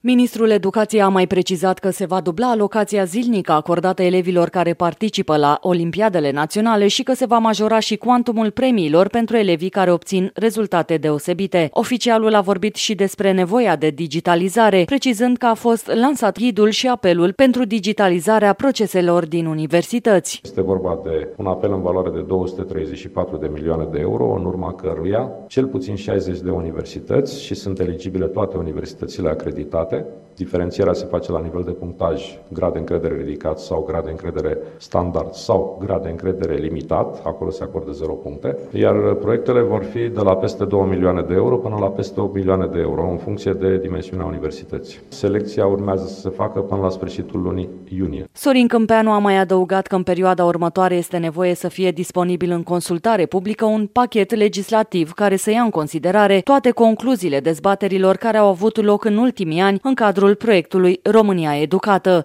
(Sorin Cîmpeanu, ministrul Educației)